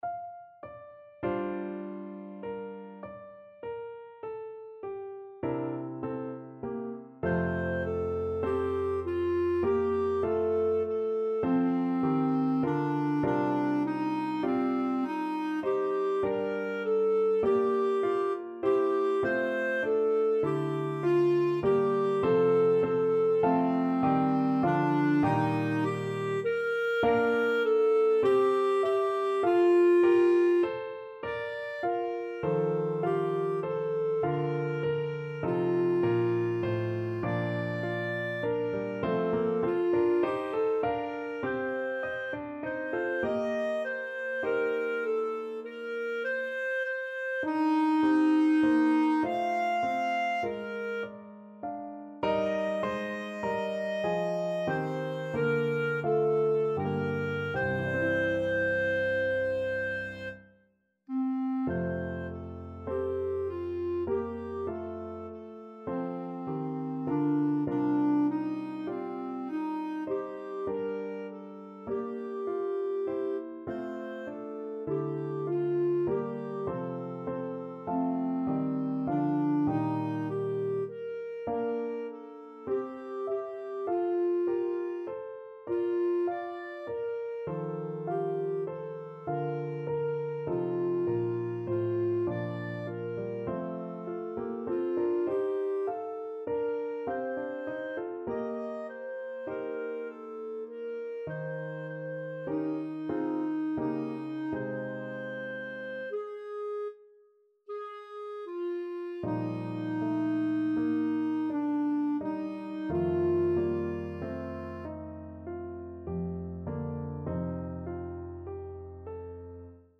5/4 (View more 5/4 Music)
Classical (View more Classical Clarinet Music)